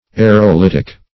Aerolitic \A`["e]r*o*lit"ic\, a.
aerolitic.mp3